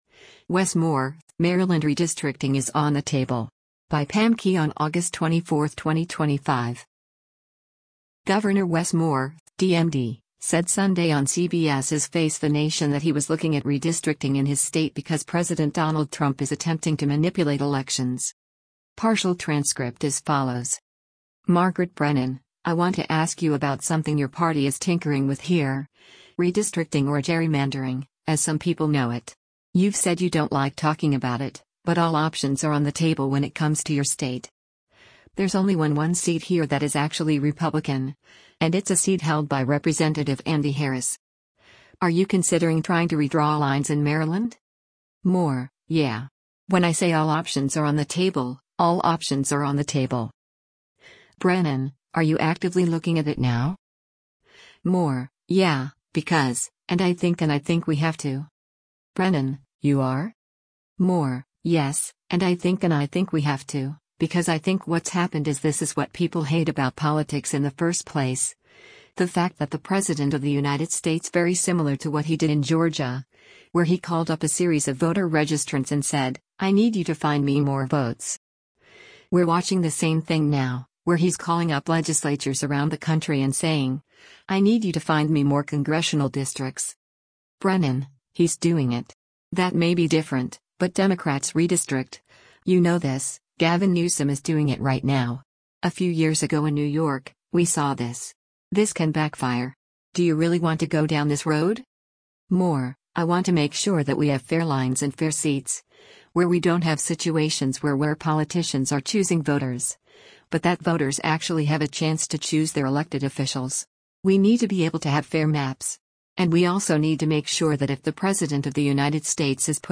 Governor Wes Moore (D-MD) said Sunday on CBS’s “Face the Nation” that he was looking at redistricting  in his state because President Donald Trump is attempting to “manipulate elections.”